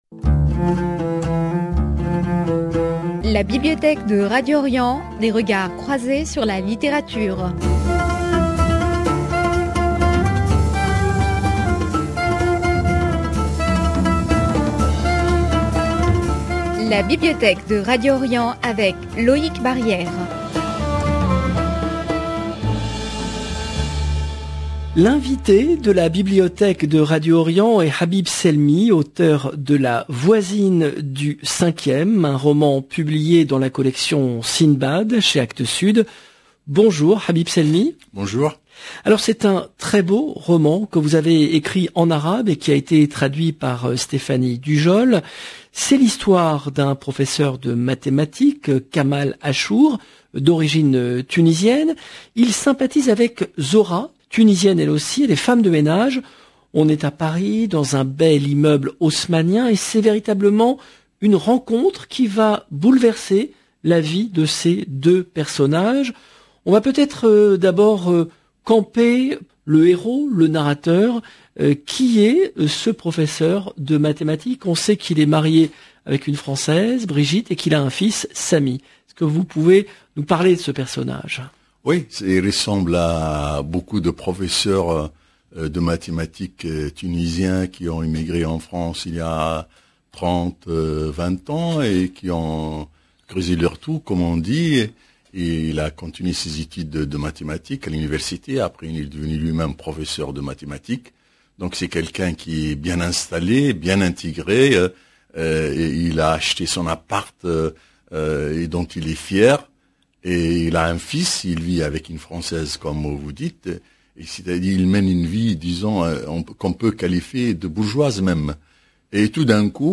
L’invité de la Bibliothèque de Radio Orient est l’écrivain tunisien de langue arabe Habib Selmi , auteur de “La voisine du cinquième”, un roman publié dans la collection Sindbad, chez Actes Sud. C’est l’histoire d’un professeur de mathématiques, Kamal Achour, d’origine tunisienne, qui sympathise avec Zohra, Tunisienne elle aussi et femme de ménage.